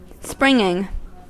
Ääntäminen
Ääntäminen US Tuntematon aksentti: IPA : /ˈsprɪŋɪŋ/ Haettu sana löytyi näillä lähdekielillä: englanti Käännös Adjektiivit 1. jaillissant {m} Springing on sanan spring partisiipin preesens.